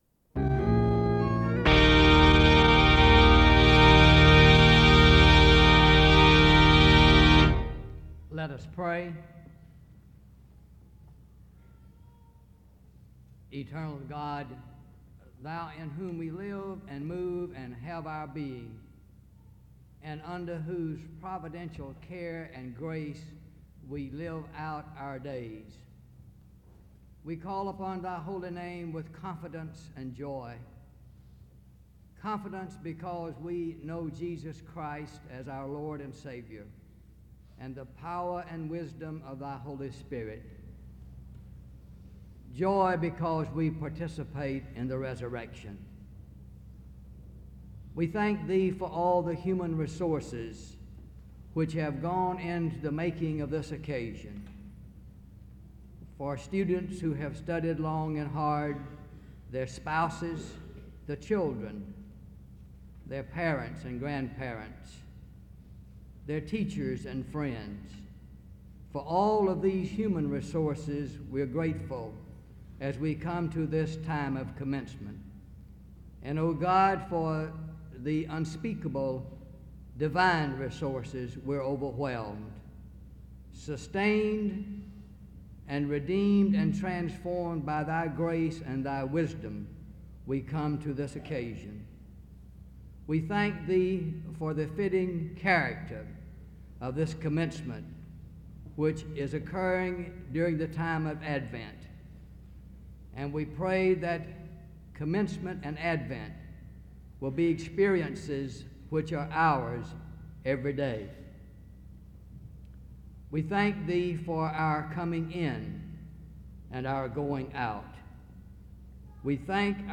SEBTS Commencement
SEBTS Chapel and Special Event Recordings